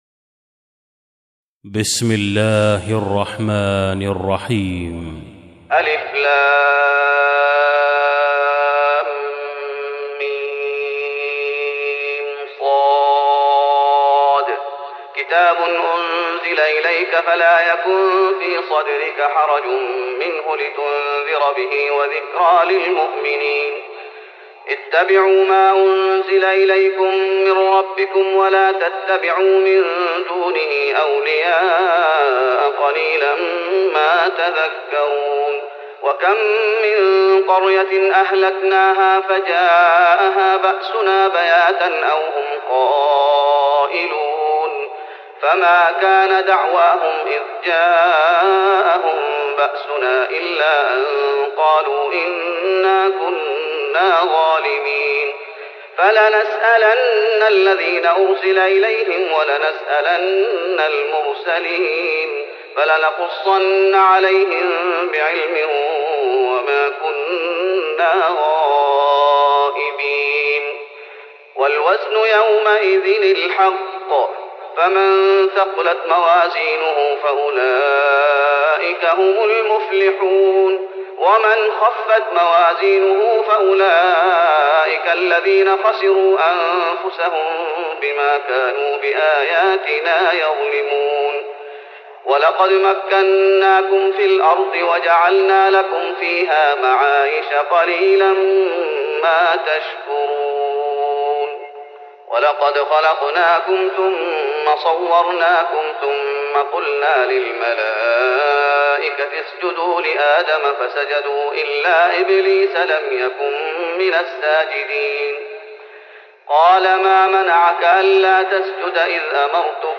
تراويح رمضان 1415هـ من سورة الأعراف (1-46) Taraweeh Ramadan 1415H from Surah Al-A’raf > تراويح الشيخ محمد أيوب بالنبوي 1415 🕌 > التراويح - تلاوات الحرمين